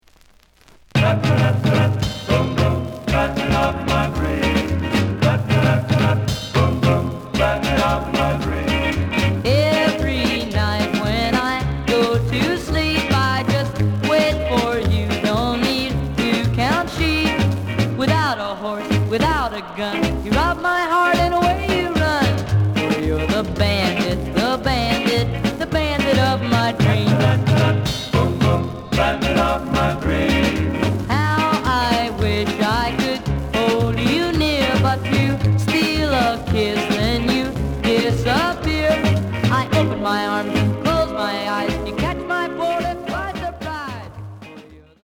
The audio sample is recorded from the actual item.
●Format: 7 inch
●Genre: Rhythm And Blues / Rock 'n' Roll
Slight noise on A side.)